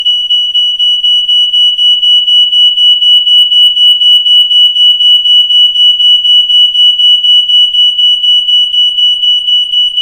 • Hitzewarnmelder (vernetzbar)
• Lautstärke im Test: 101,1 dBA
ei603tyc-hitzewarnmelder-alarm.mp3